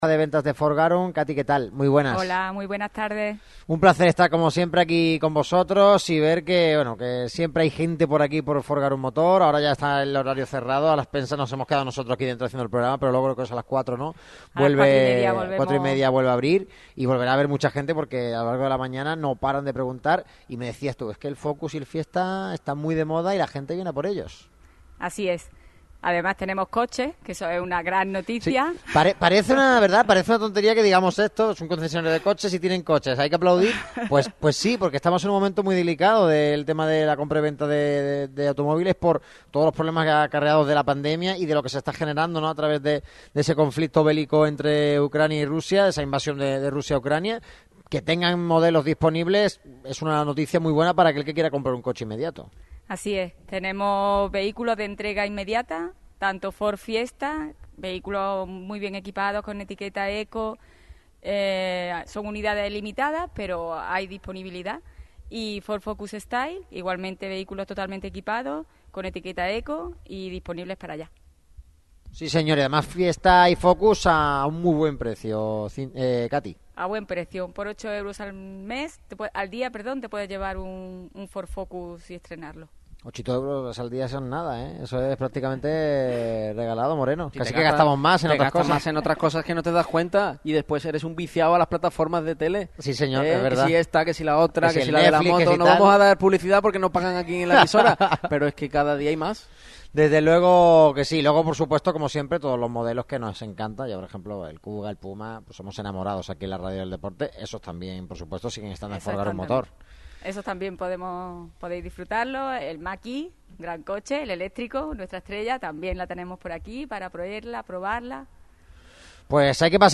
Radio MARCA se llevó su estudio móvil hasta Garum Motor | RMM